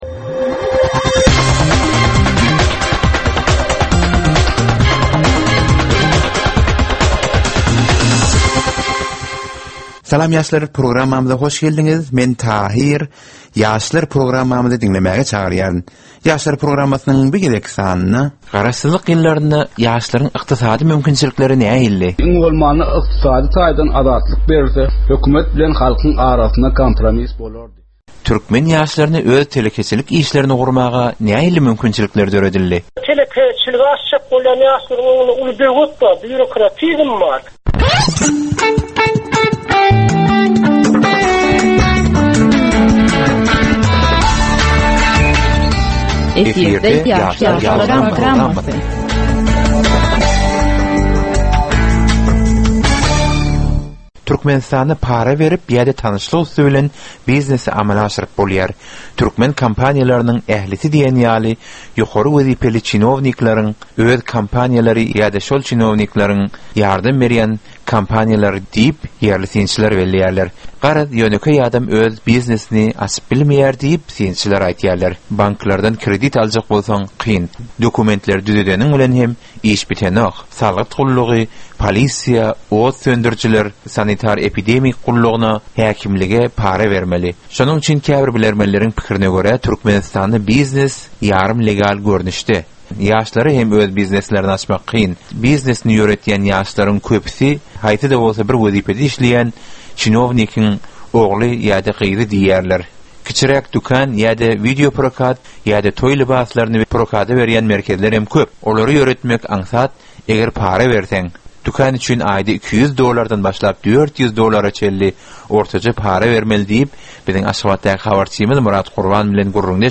Türkmen we halkara yaşlarynyň durmuşyna degişli derwaýys meselelere we täzeliklere bagyşlanylyp taýýarlanylýan 15 minutlyk ýörite gepleşik. Bu gepleşikde ýaşlaryn durmuşyna degişli dürli täzelikler we derwaýys meseleler barada maglumatlar, synlar, bu meseleler boýunça adaty ýaşlaryň, synçylaryň we bilermenleriň pikrileri, teklipleri we diskussiýalary berilýär. Gepleşigiň dowamynda aýdym-sazlar hem eşitdirilýär.